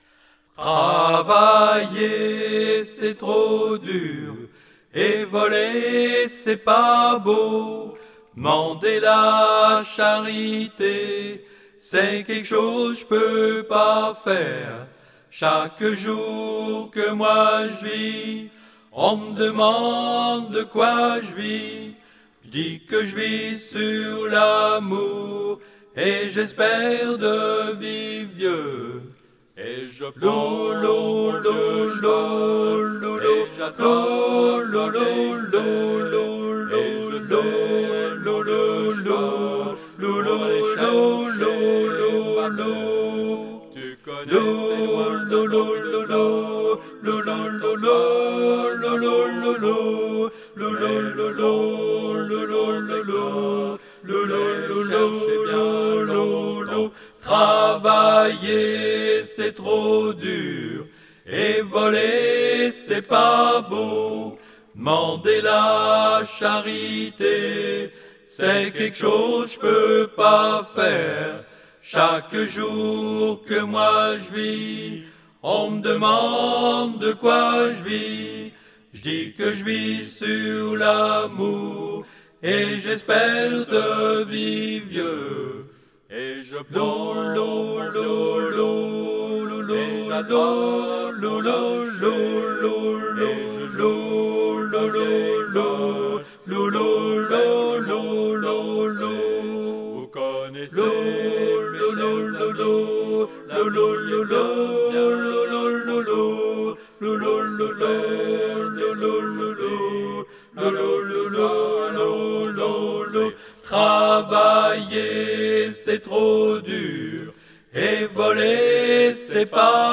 traditionnel cajun
Soprano